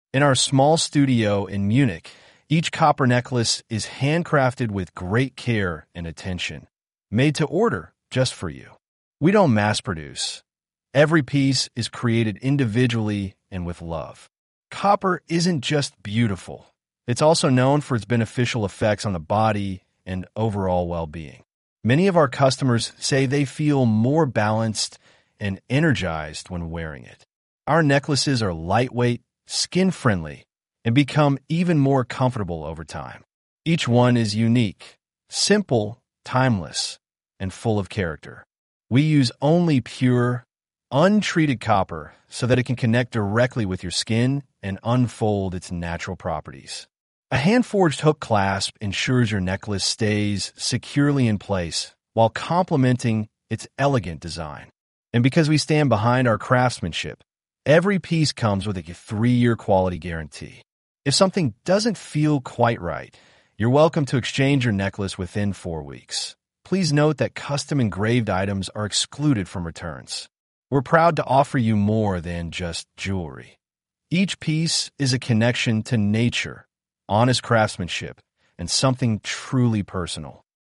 Halskete-engl-rttsreader.mp3